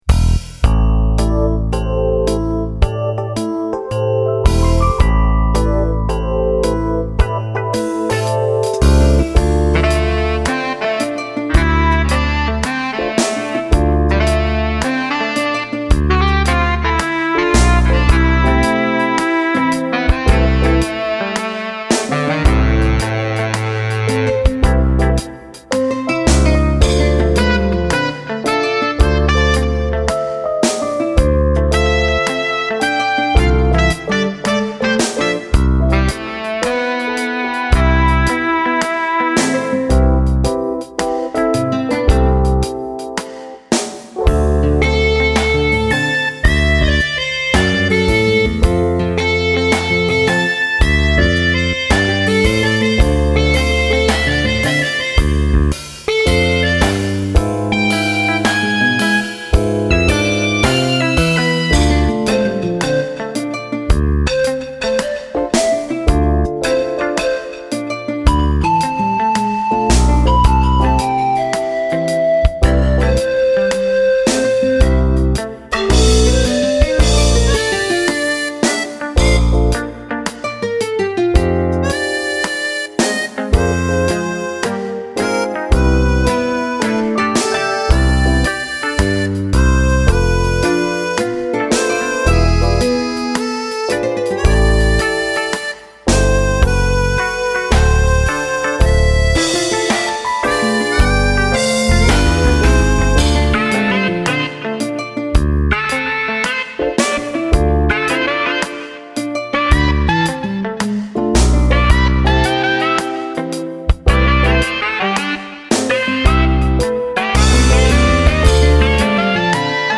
サイドの楽器たちのそれぞれ違うメロディーが絡んで全体が前に前に進む雰囲気がお気に入り。